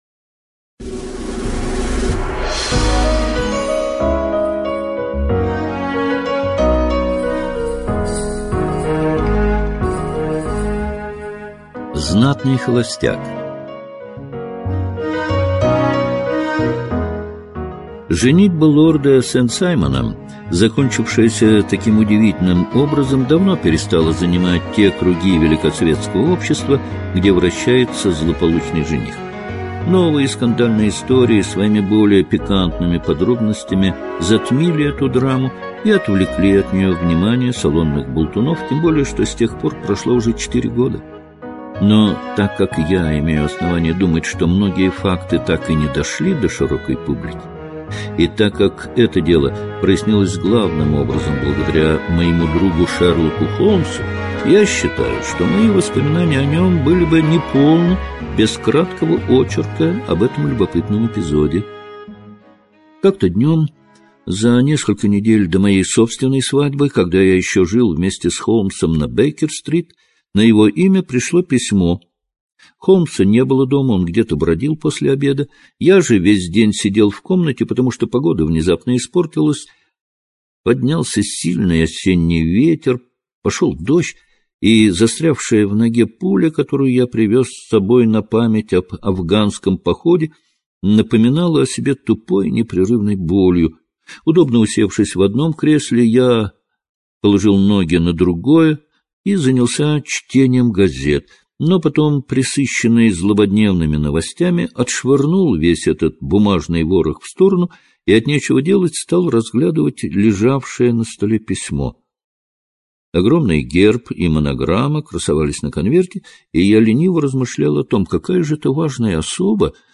Знатный холостяк — слушать аудиосказку Артур Конан Дойл бесплатно онлайн